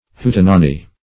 hootenanny \hoot"en*an*ny\ n.
hootenanny.mp3